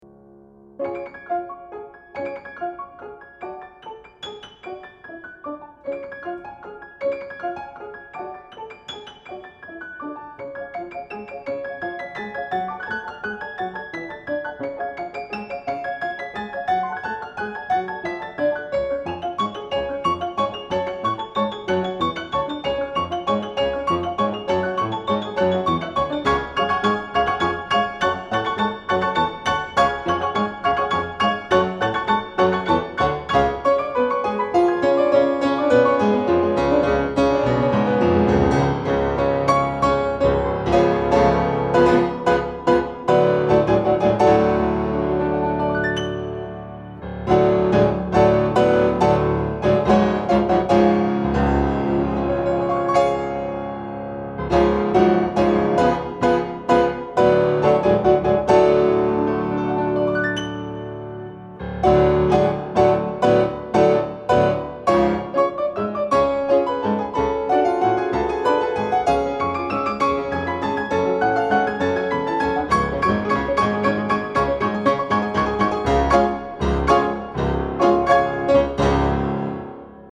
Delightful Jazz Tunes for Two Pianos
The superb engineering also helps.